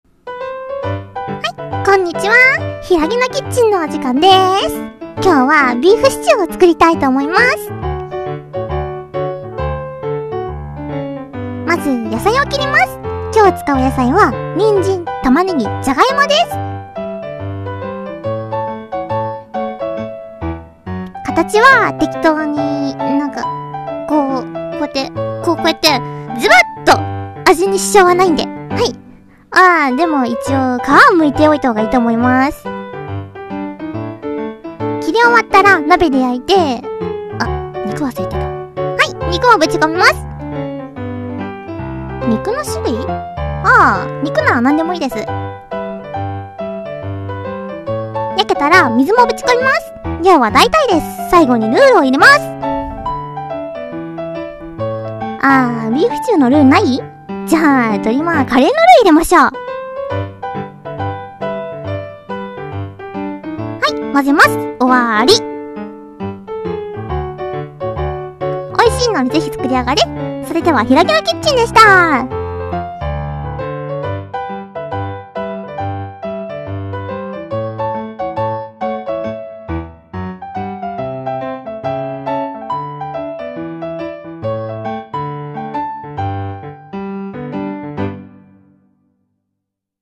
【二人声劇】適当クッキング